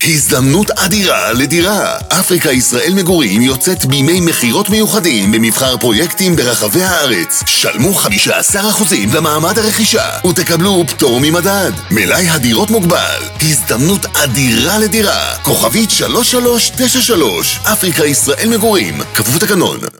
תשדירי רדיו לדוגמה